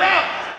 Southside Vox (16).wav